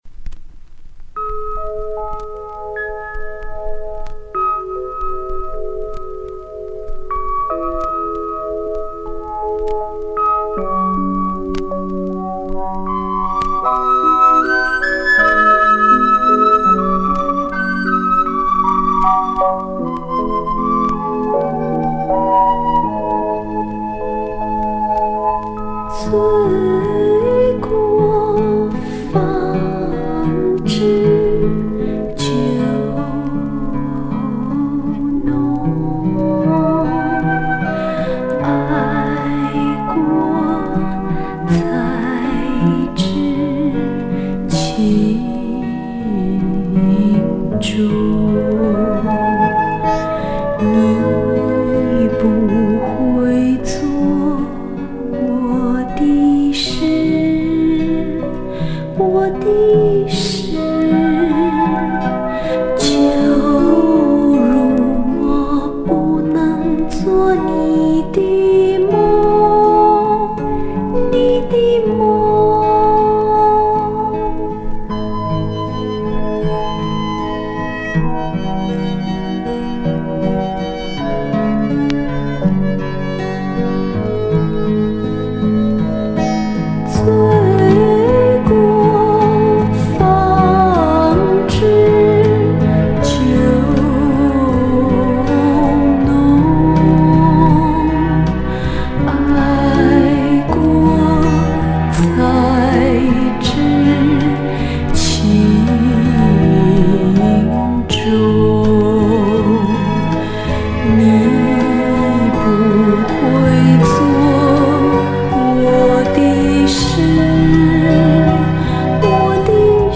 磁带有点沙音，请谅解 甜甜柔柔的嗓音和纯纯的玉女形象，当年也颇受歌迷的喜爱。